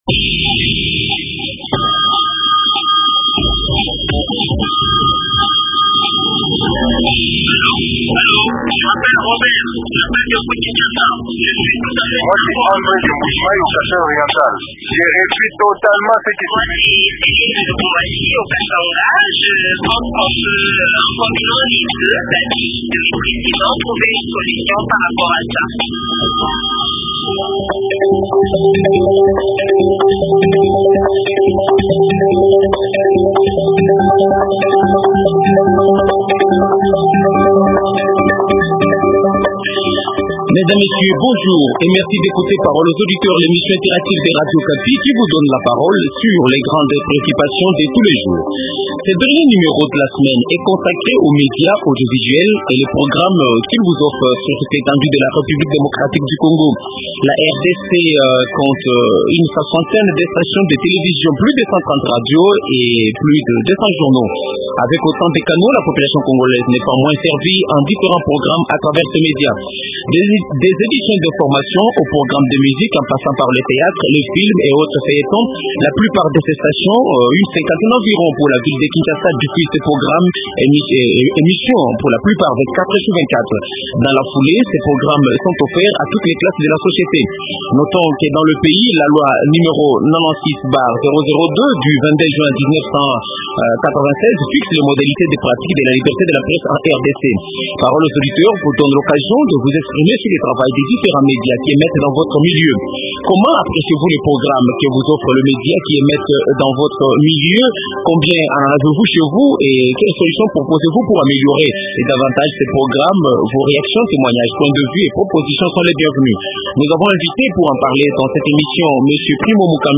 Primo Mukambilwa parle des médias audiovisuels congolais
Invité:M. Primo Mukambilwa, président de la haute autorité des médias.